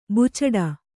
♪ bucaḍe